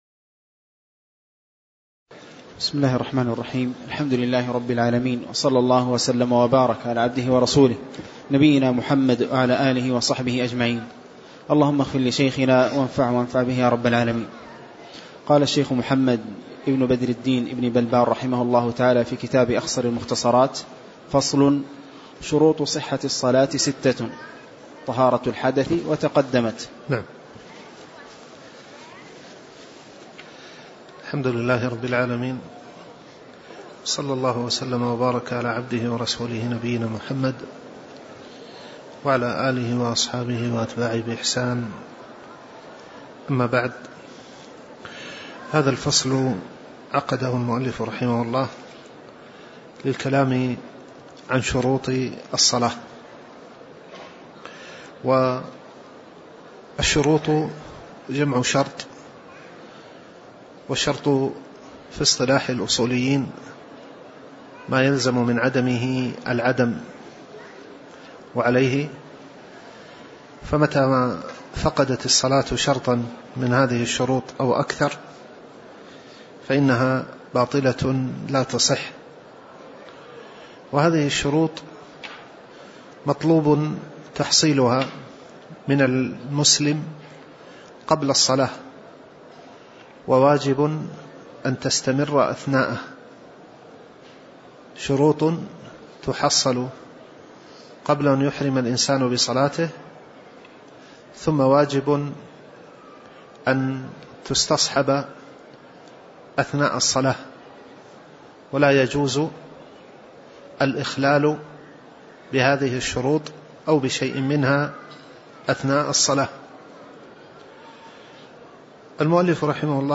تاريخ النشر ٢٠ جمادى الأولى ١٤٣٩ هـ المكان: المسجد النبوي الشيخ